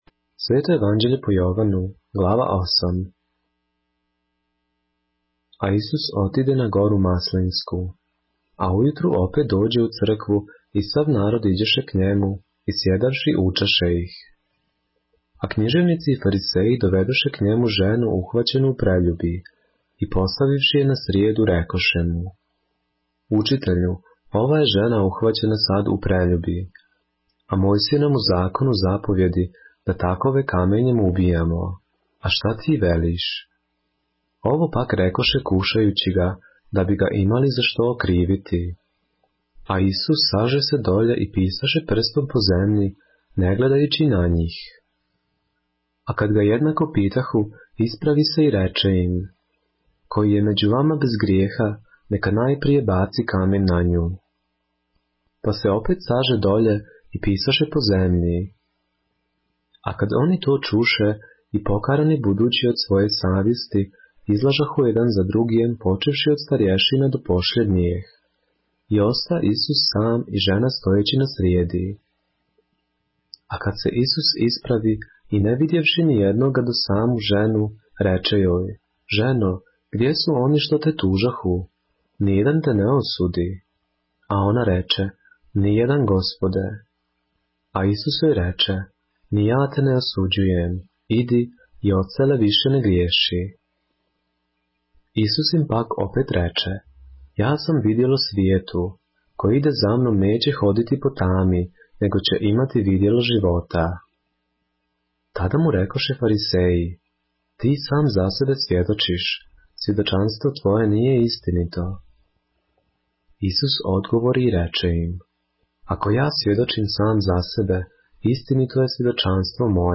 поглавље српске Библије - са аудио нарације - John, chapter 8 of the Holy Bible in the Serbian language